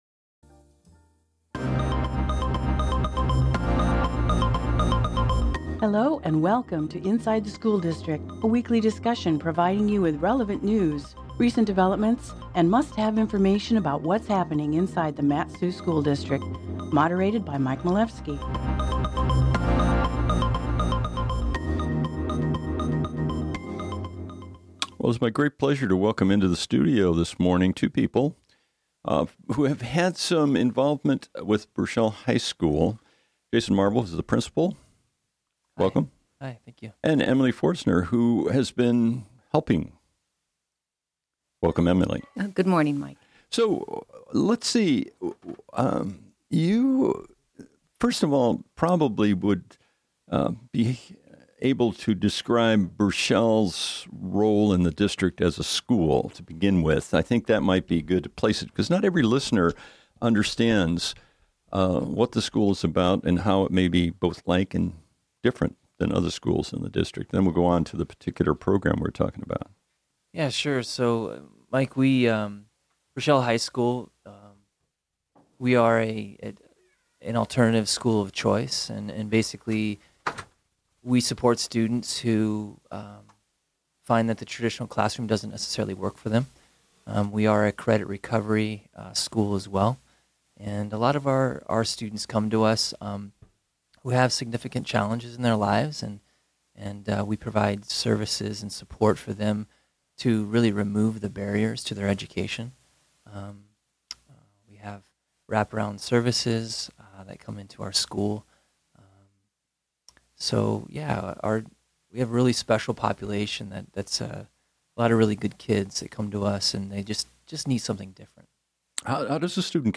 Aug 25, 2017 | Inside the District, Morning Show Interviews